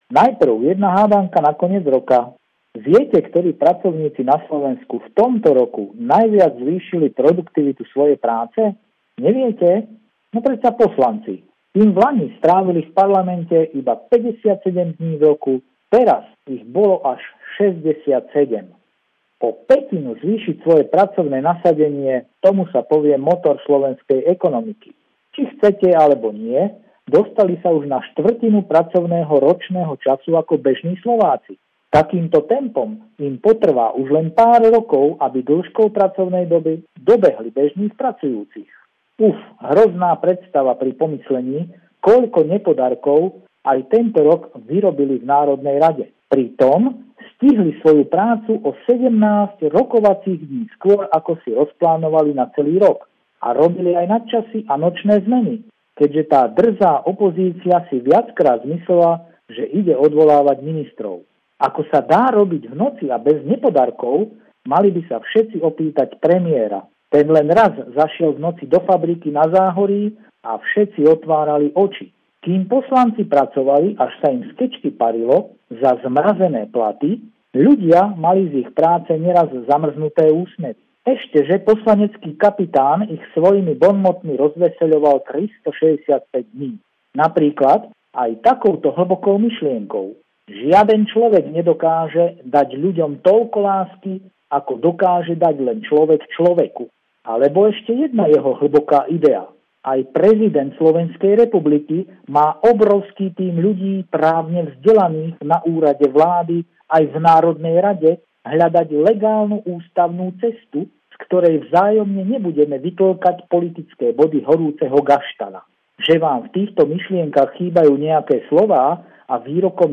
(Ne)pravidelný, silvestrovský telefonát týždňa